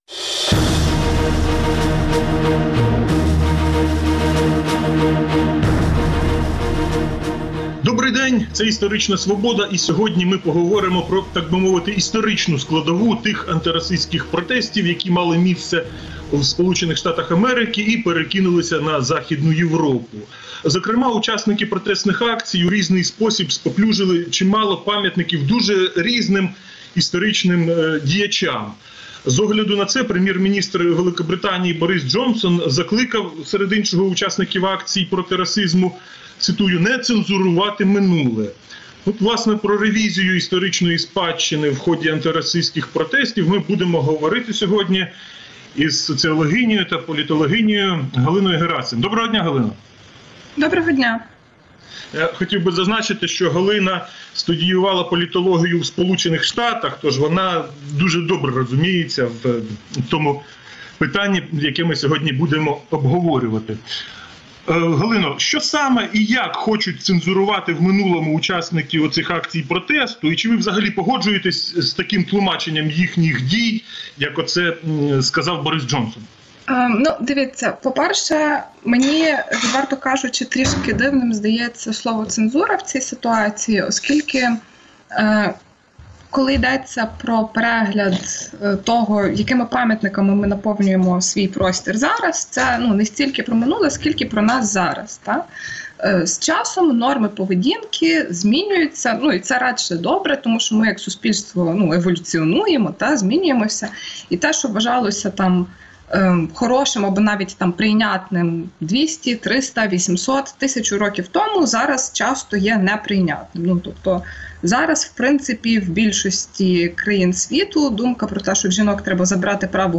Гість програми